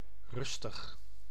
Ääntäminen
IPA: [ɾʏs.tɪɣ]